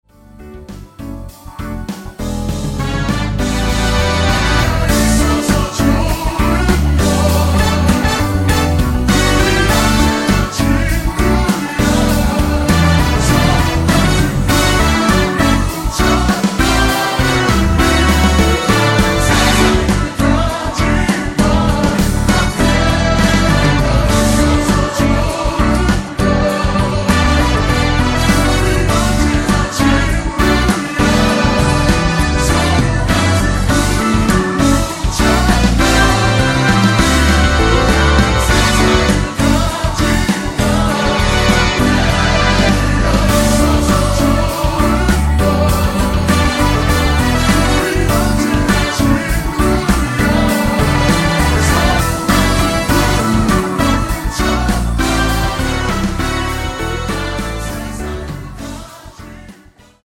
원곡 3분 지나서 부터 나오는 코러스 포함된 MR입니다.(미리듣기 확인)
원키에서(-3)내린 코러스 포함된 MR입니다.
D
앞부분30초, 뒷부분30초씩 편집해서 올려 드리고 있습니다.